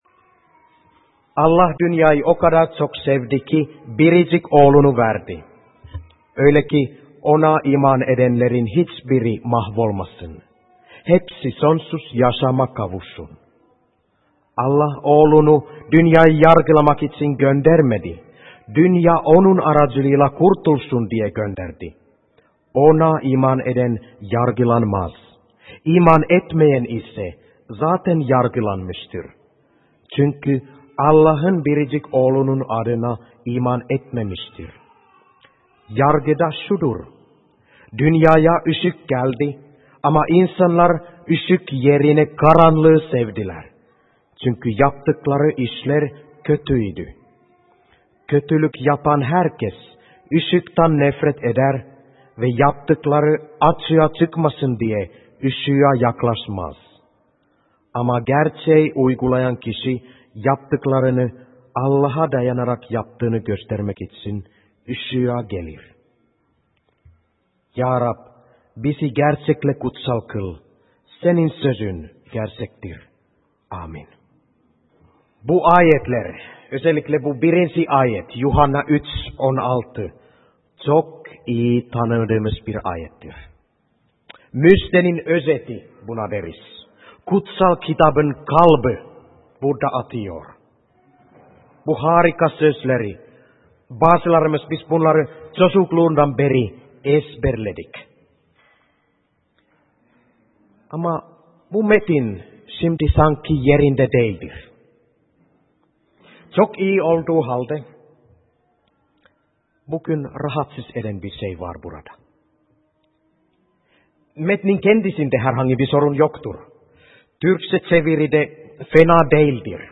2015 yılı: Yuhanna kitabından vaazlar
(Pentekost Bayramı)